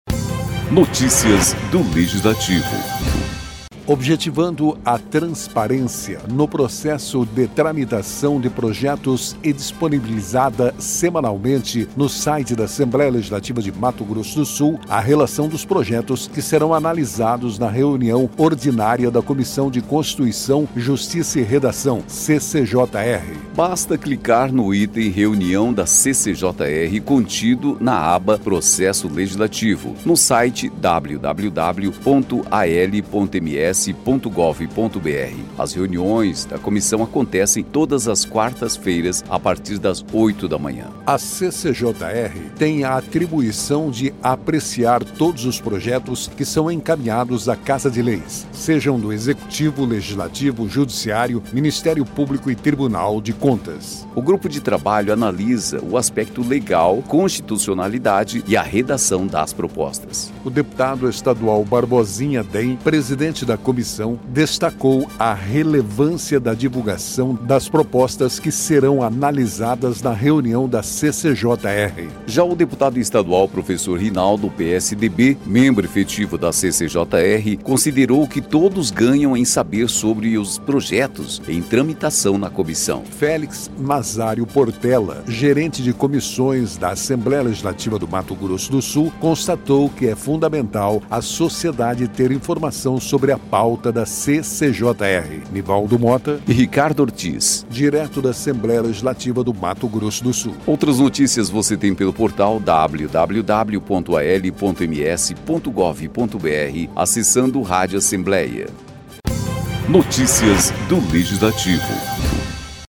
O deputado estadual Barbosinha (DEM), presidente da comissão, destacou a relevância da divulgação das propostas que serão analisadas na reunião da CCJR.